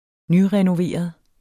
Udtale [ -ʁεnoˌveˀʌð ]